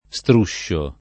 Str2ššo] s. m. («lo strusciare; passeggio»); pl. -sci — es.: si sente, Qua presso, uno struscio di gente [